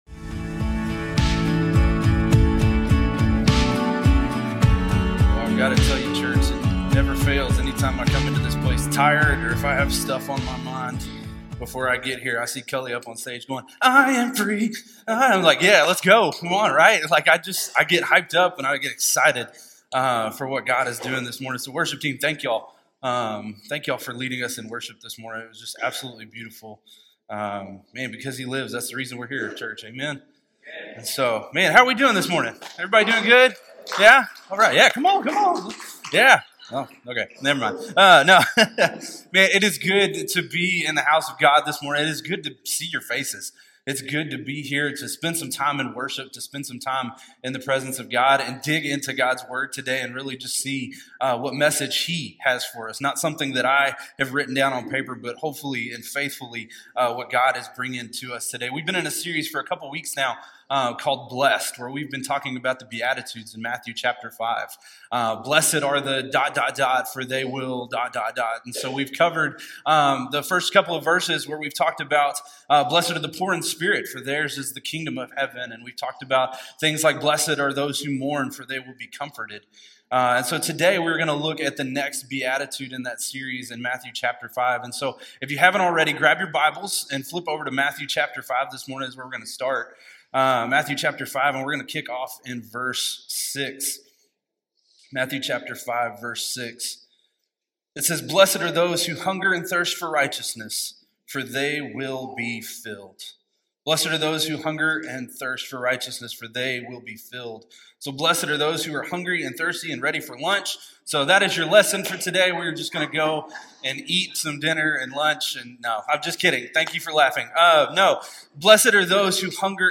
The sermon explores two foundational truths: